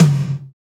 TOM DEEP 1JR.wav